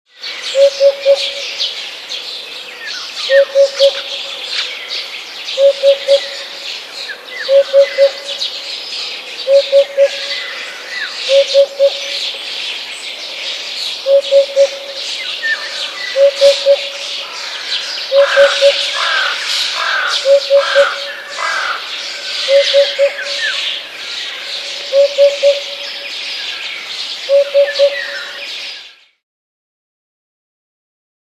Pica-pau - poupa cantante
• Categoria: Poupa